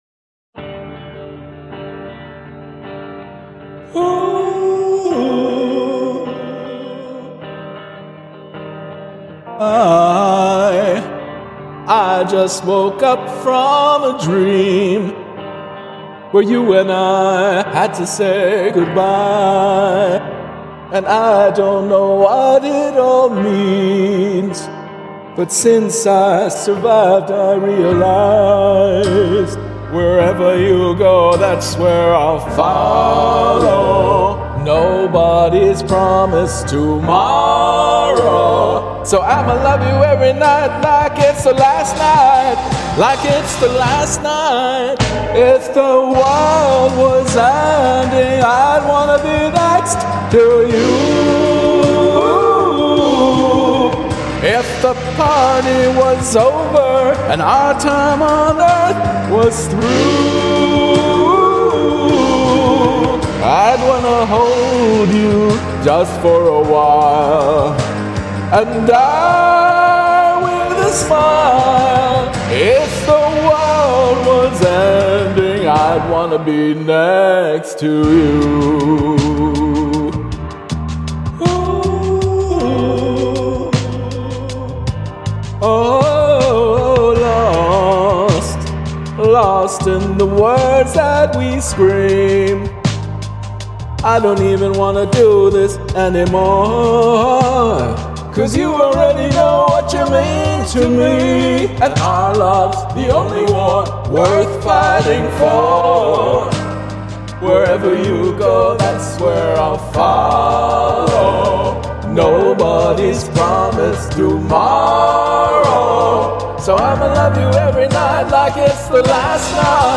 How to deal with a lower voice.